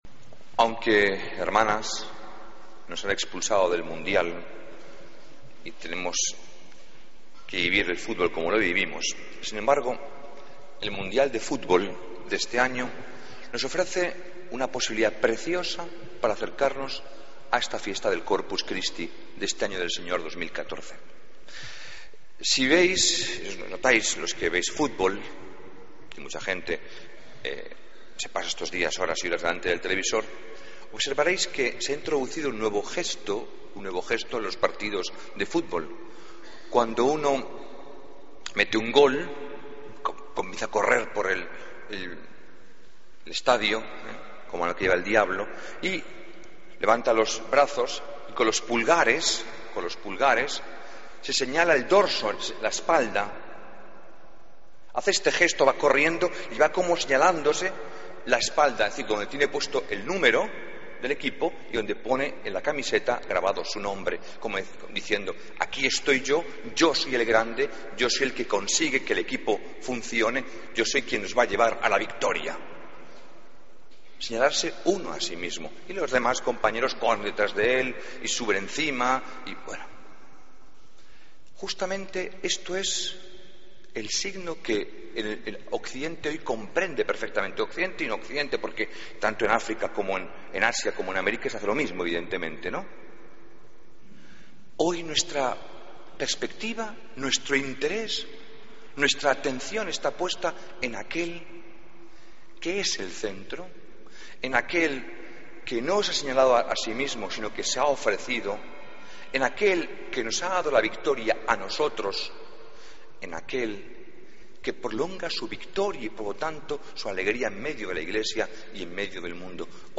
Homilía del Domingo 22 de Junio de 2014